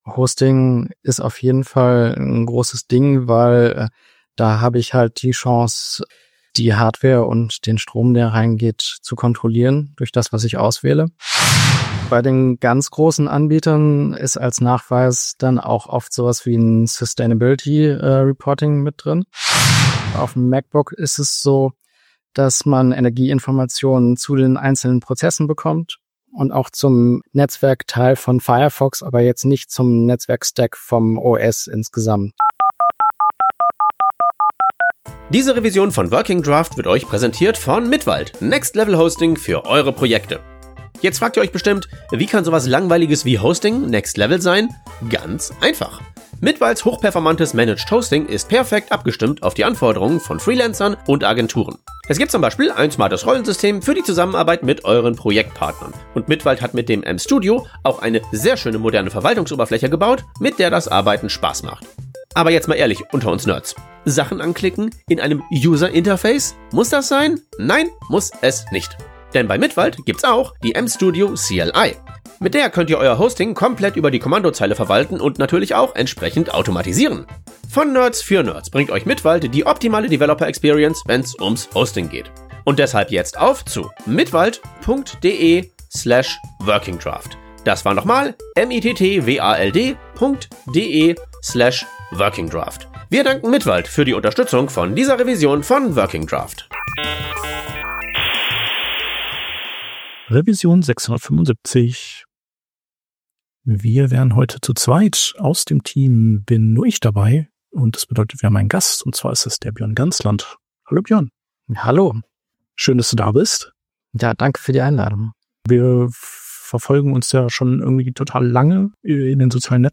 Wöchentlicher Podcast für Frontend Devs, Design Engineers und Web-Entwickler:innen